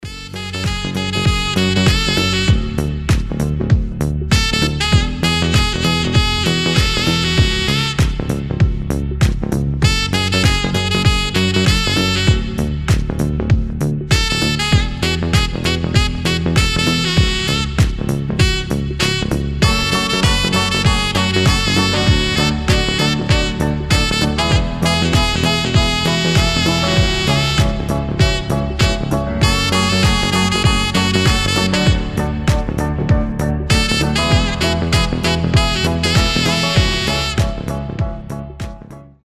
Танцевальные рингтоны
Рингтоны без слов
Веселые рингтоны
Cover
Инструментальные